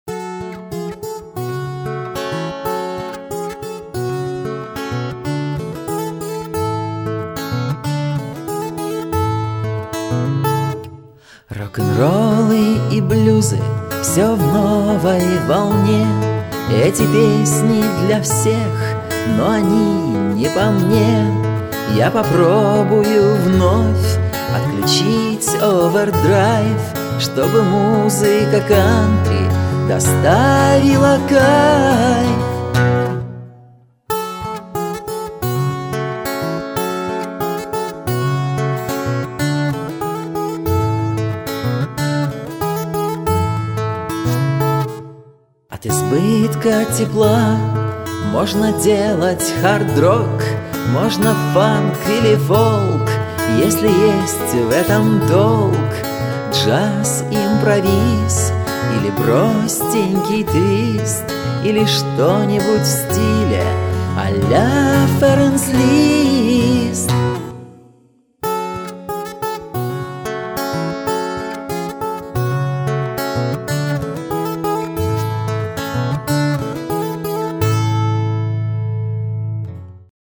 Есть в альбоме и Рок`н`Ролл
вокал, бэк вокал, акустические гитары